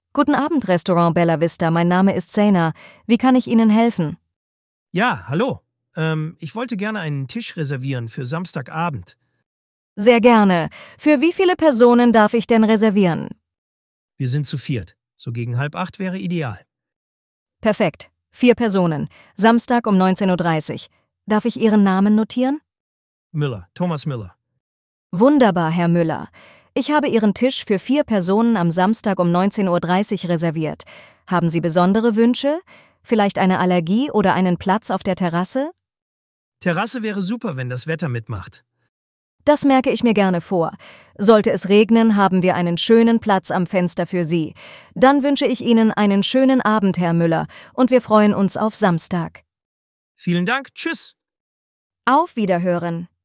Wählen Sie Ihre Sprache und hören Sie echte KI-Gespräche.
voice-demo-restaurant.mp3